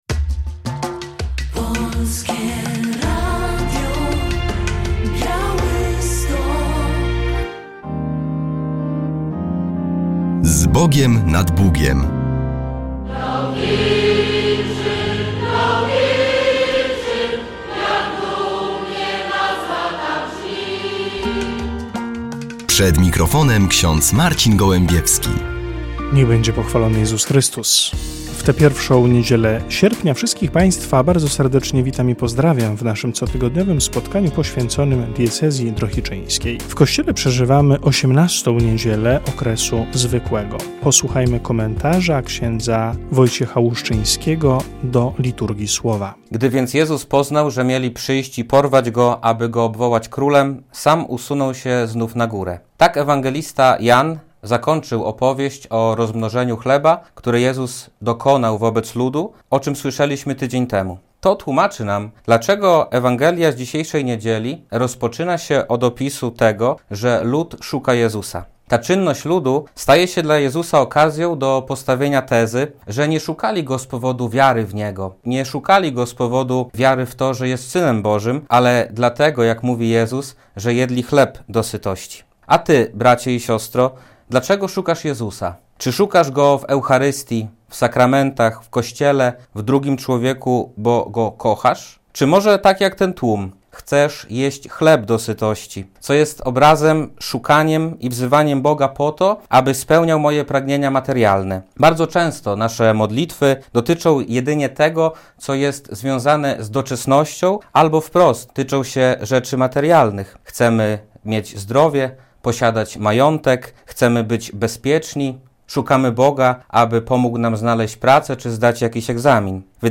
W audycji fragment homilii bpa Piotra Sawczuka wygłoszonej z okazji rozpoczęcia 34. Pieszej Pielgrzymi Drohiczyńskiej na Jasną Górę.